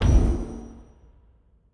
Fantasy Click (2).wav